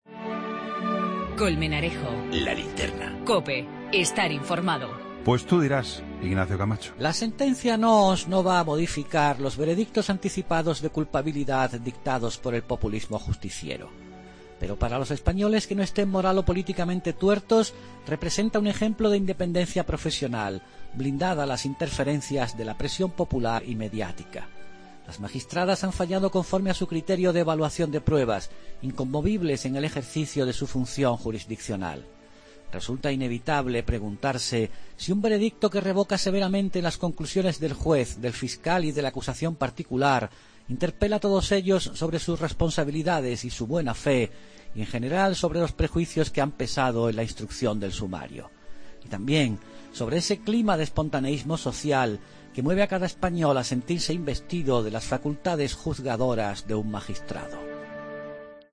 AUDIO: El comentario de Ignacio Camacho en 'La Linterna' sobre la sentencia del Caso Nóos